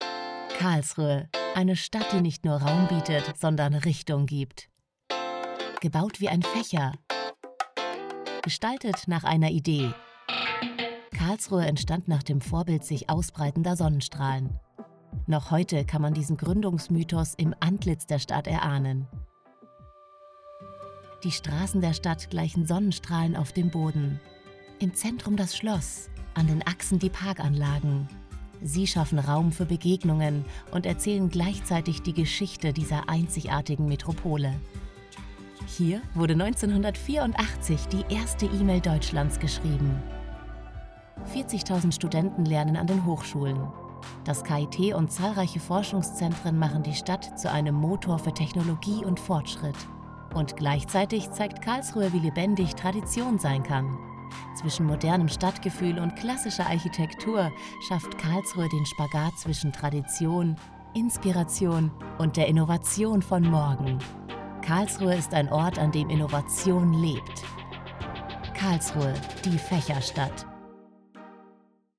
dunkel, sonor, souverän, hell, fein, zart, markant, plakativ, sehr variabel
Mittel minus (25-45)
Imagefilm Stadt
Presentation, Scene